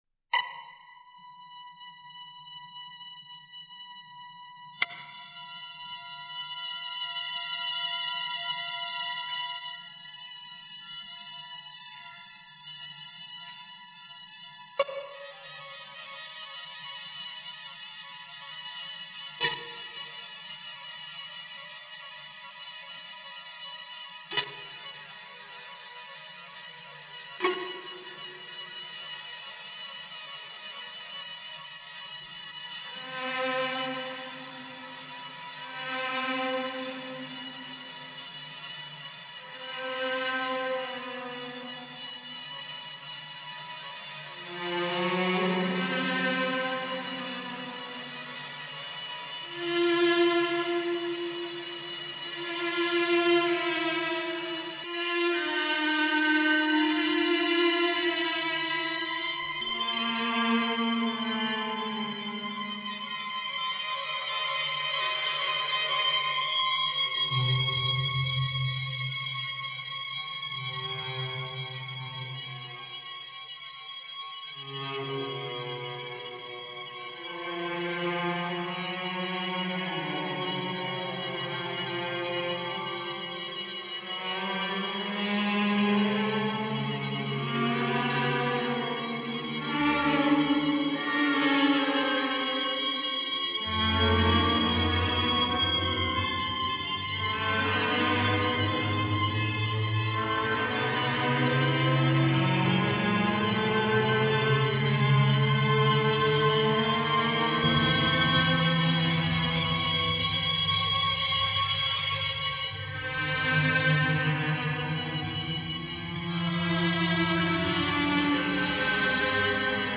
As., Grabación Privada, 2001